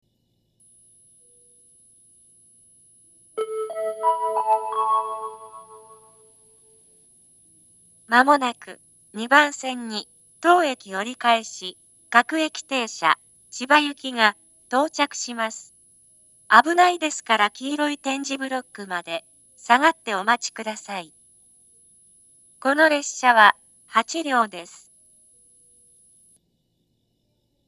２０１７年１月１８日には放送装置が更新され、自動放送が合成音声に変更されました。
２番線接近放送
音程は低いです。